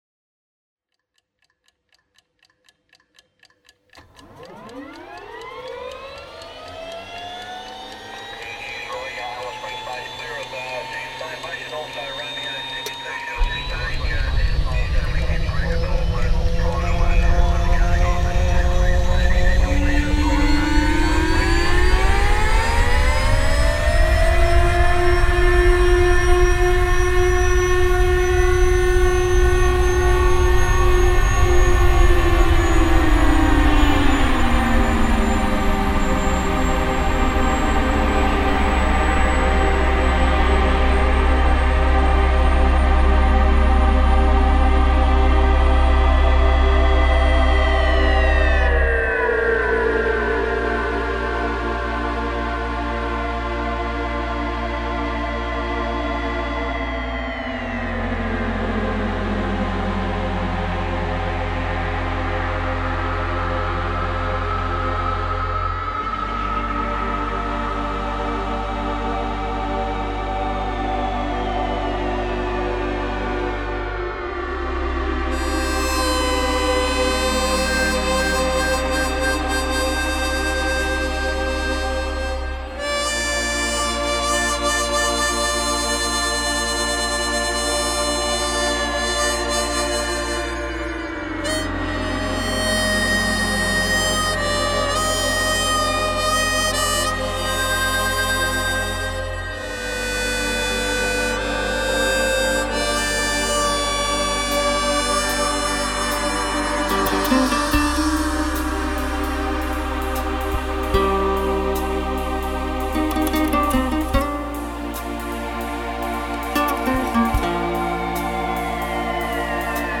Roland Alpha Juno 2, Korg 01/W fd, Roland XP 10, Roland SPD 20, Harmonica Hohner, Guitare acoustique, Guitare électrique Fender Stratocaster avec Livepod XT live et Ebow.
Difficile les « bending notes ». Mais je suis assez content du résultat, malgré que le son de guitare acoustique ne soit pas aussi clean que je l’aurais souhaité.